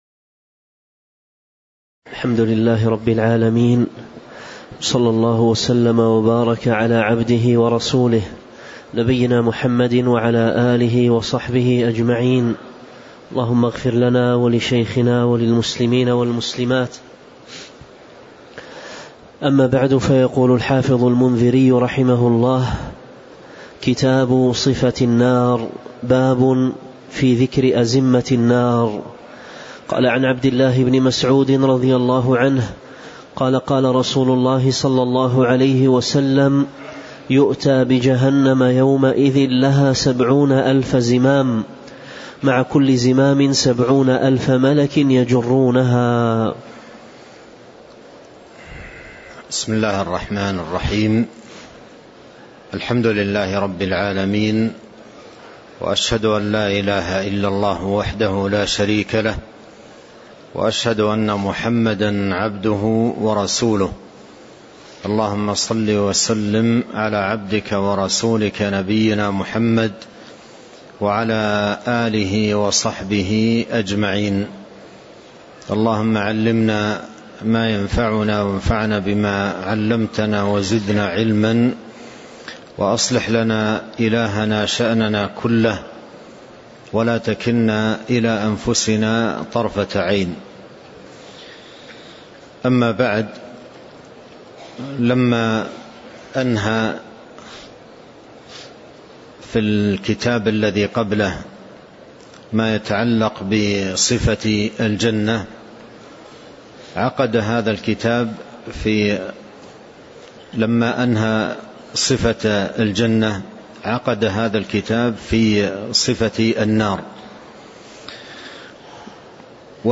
تاريخ النشر ٢٧ ذو الحجة ١٤٤٣ هـ المكان: المسجد النبوي الشيخ